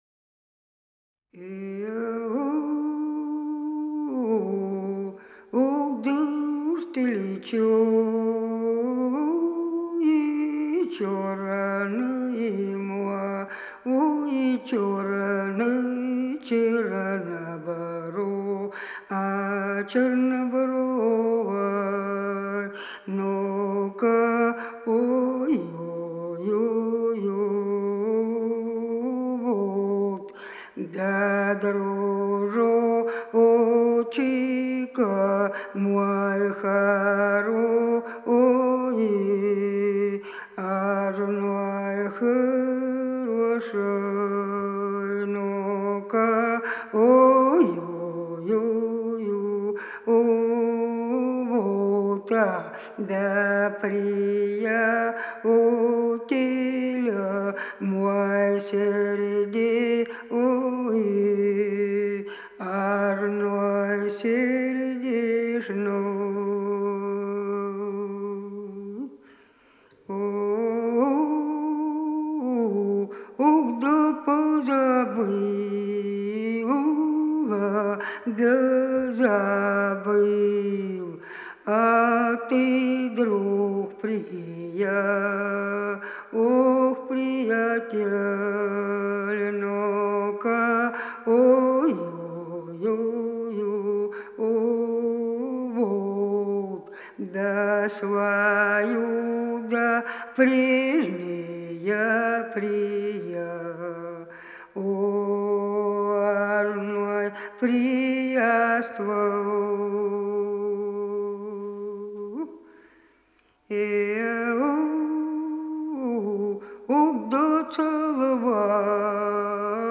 Голоса уходящего века (село Подсереднее) Уж ты чёрнай мой